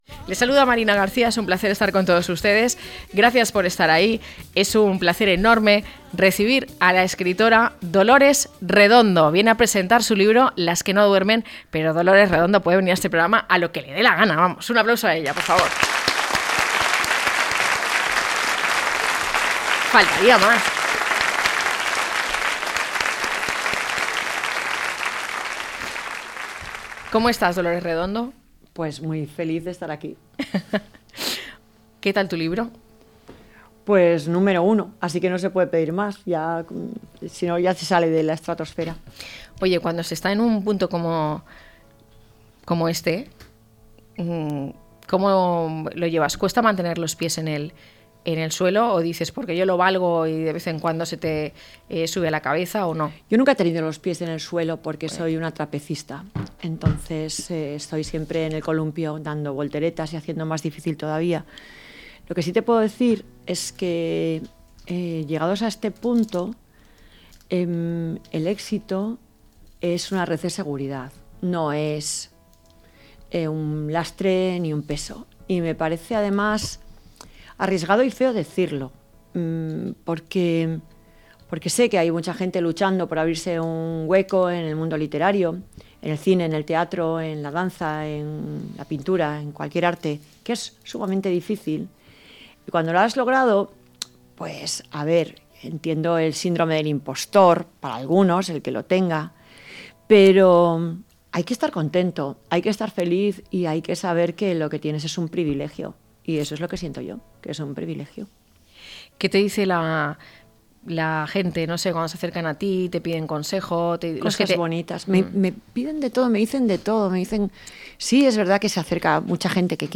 DOLORES REDONDO PRESENTA LAS QUE NO DUERMEN Recibimos a la reconocida escritora Dolores Redondo, quien presenta su nueva novela Las que no duermen, Nash, la segunda parte de “Los valles tranquilos”, su nueva saga literaria tras la exitosa “Trilogía del Baztán” y Todo esto te daré, novela por la cual ganó el Premio Planeta en 2016. La psicóloga forense Nash Elizondo documenta el origen de una leyenda sobre brujería en uno de los Valles Tranquilos de Navarra, cuando descubre el cadáver de una joven desaparecida tres años atrás.